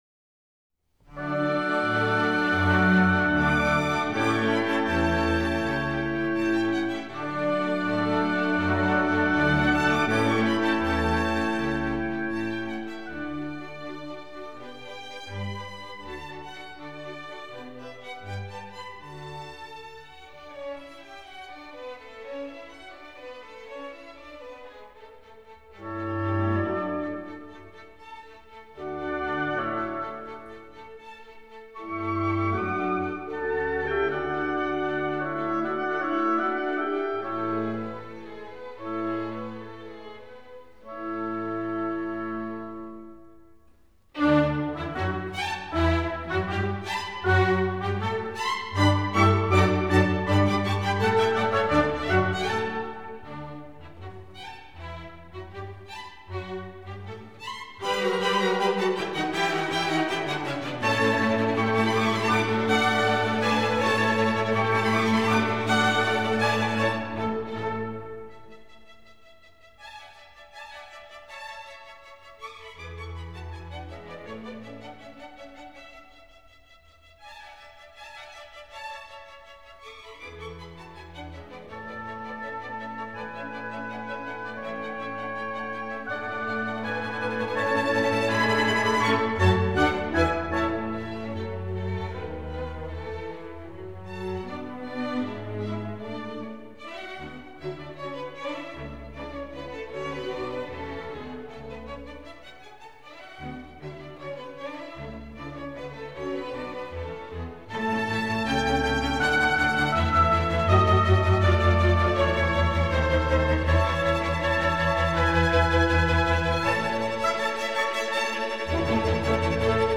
（当時猫に聴かせたLPは現在再生できず､代わりに別の演奏を。約10MB MP3）
どうも、軽やかで諧謔的なメロディーが好みのようで、重厚長大な曲をかけると知らぬ間にいなくなった。